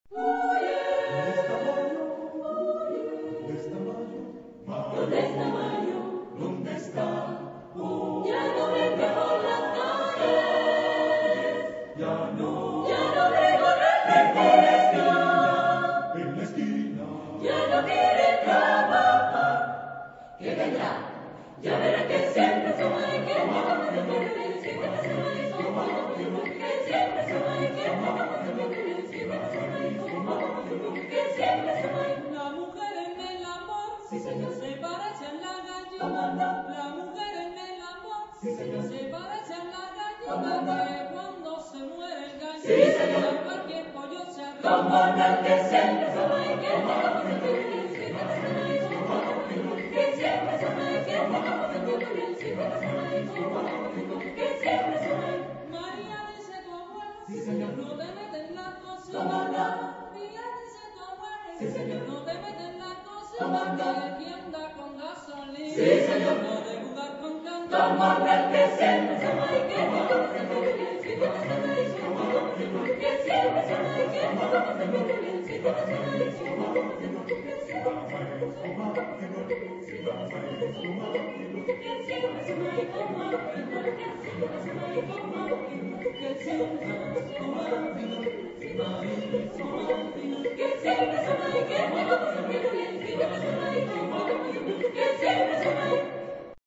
Género/Estilo/Forma: Profano ; Popular ; Danza
Tipo de formación coral: SATB  (4 voces Coro mixto )
Solistas : ST  (2 solista(s) )
Tonalidad : fa mayor